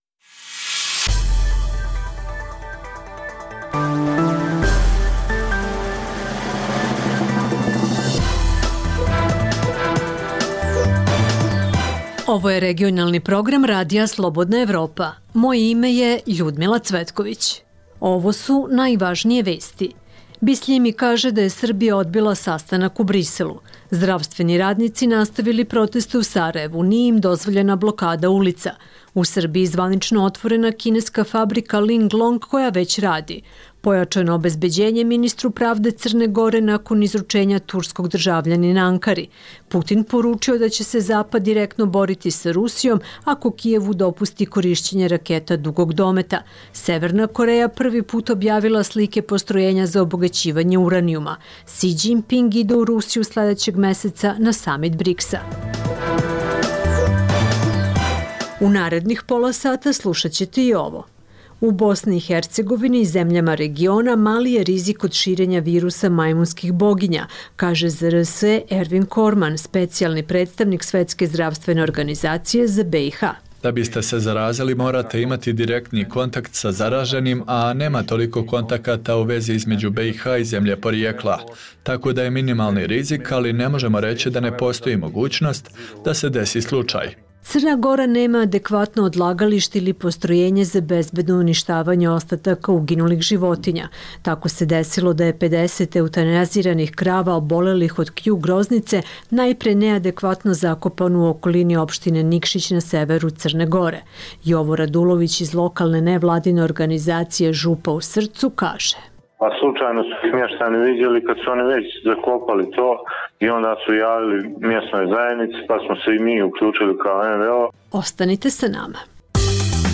Poslušajte Aktuelno, regionalni radijski program
Dnevna informativna emisija Radija Slobodna Evropa o događajima u regionu i u svijetu. Vijesti, teme, analize i komentari.